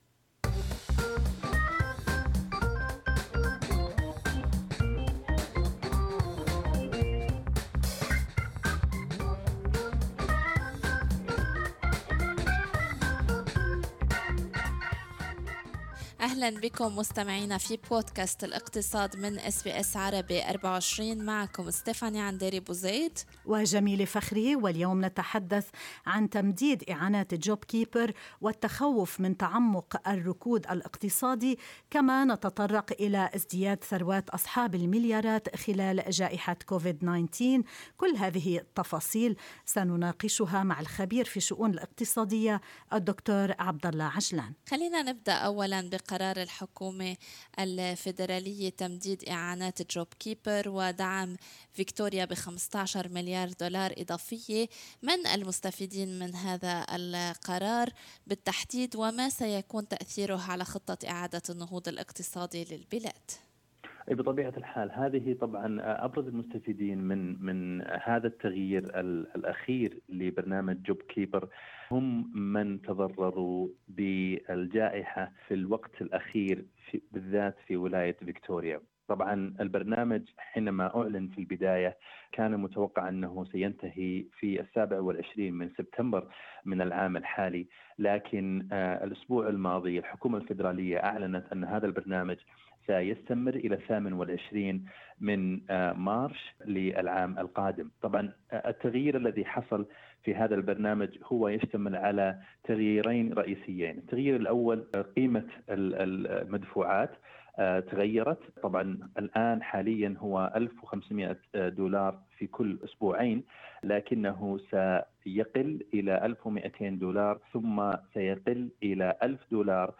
اللقاء الكامل مع الخبير في الشؤون الاقتصادية